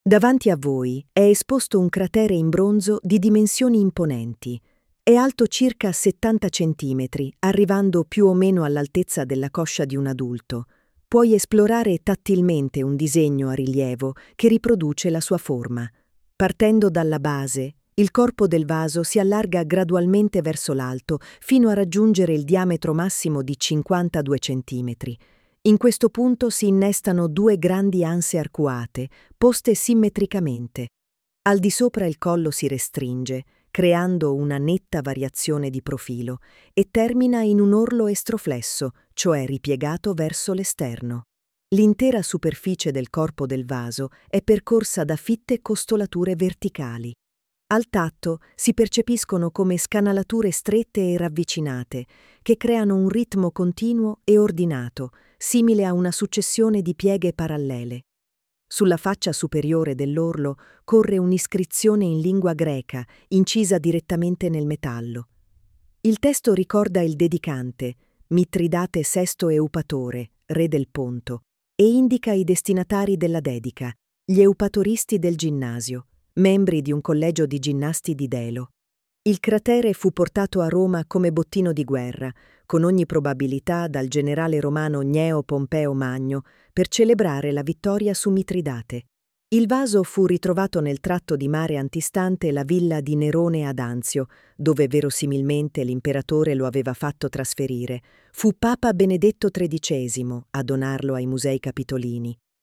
•    9 AUDIODESCRIZIONI che accompagnano il visitatore nell’esplorazione delle opere, ognuna indicata da didascalia in Braille e guida audio, con il relativo testo, fruibile tramite QR code: